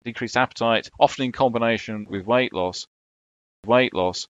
Native speakers very often don’t audibly release final t when a consonant follows: the air is stopped, and there’s a brief silence, but this leads directly into the following consonant.
And here are some native speakers saying weight loss without a clear explosion of the final t: